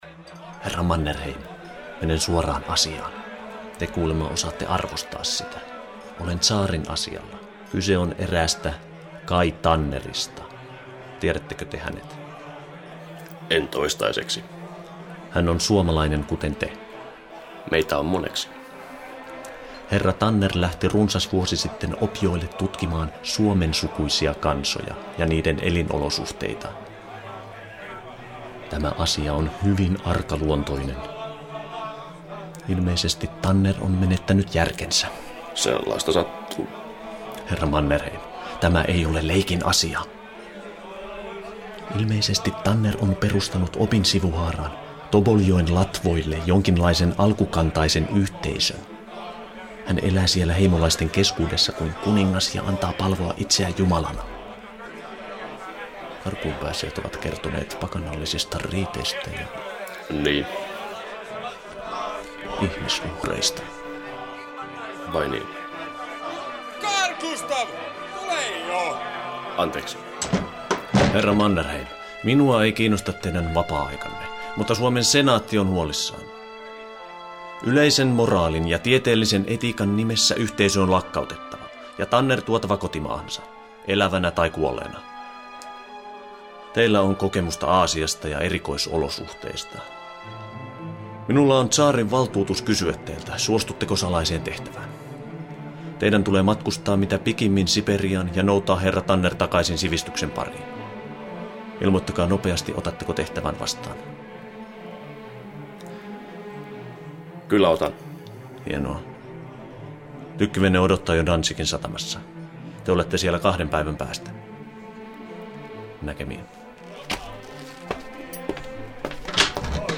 --RADIO DRAMAS--